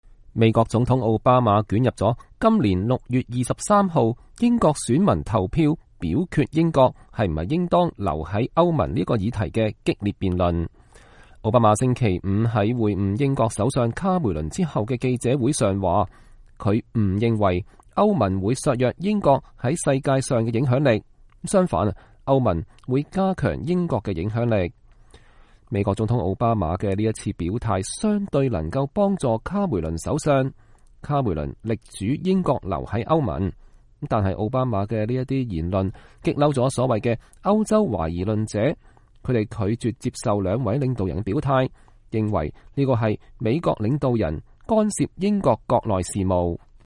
美國總統奧巴馬捲入今年6月23日英國選民投票表決英國是否應當留在歐盟這一議題的激烈辯論。奧巴馬星期五在會晤英國首相卡梅倫後的記者會上說，“我不認為歐盟會削弱英國在世界的影響力，相反歐盟會加強英國影響力。”